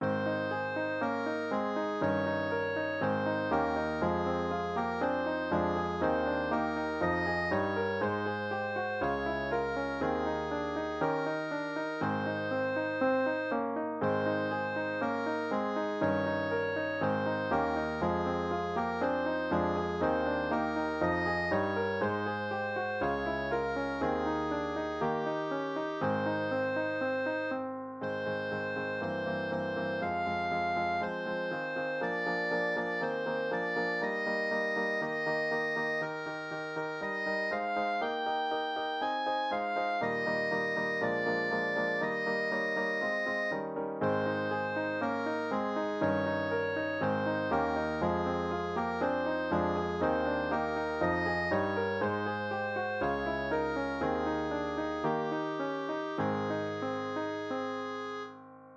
045.c-Liðinn er dagur (solo+piano)-2